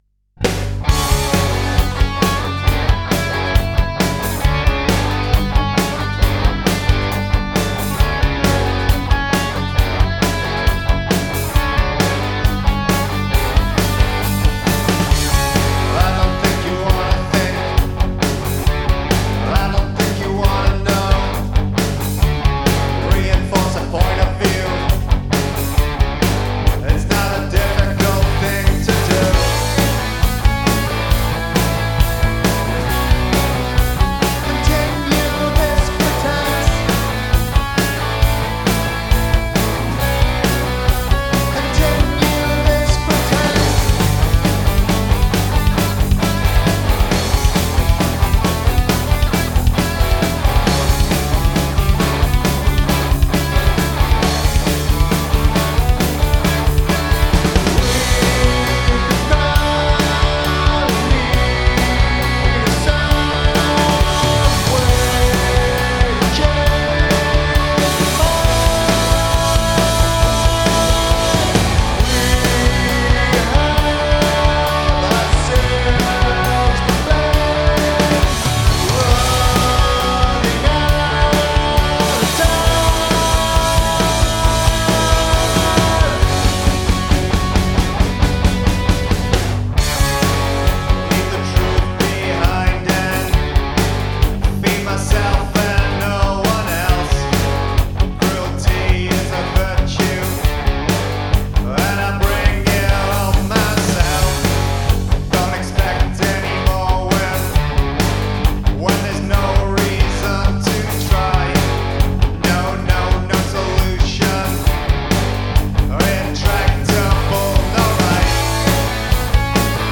The chorus just starts, its like there's no build up to it. It definitely needs a bigger drum fill on the way in but I think it might be more than that. Can't decide whether the chorus vocal needs a full scream a bit of a scream or something else. Sounds gutless as it is.
The end is weird - needs and extra couple of bars - feels like it ends too quick.